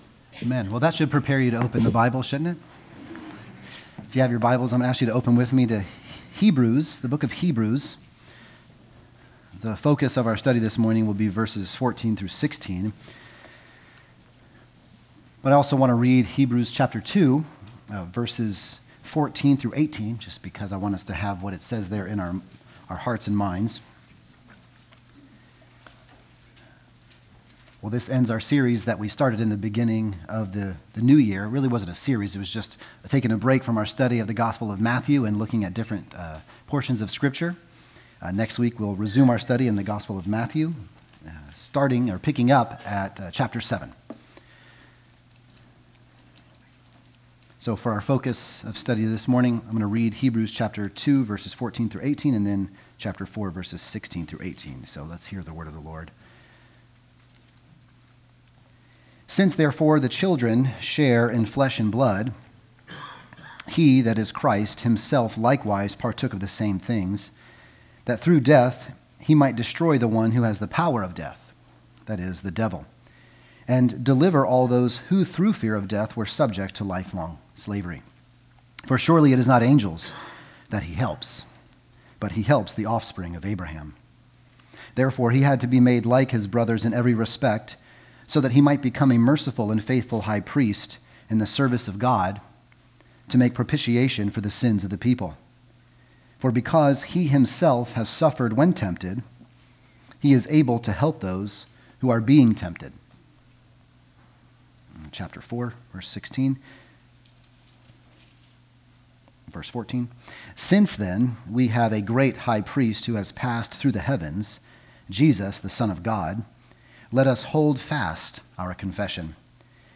( Sunday AM )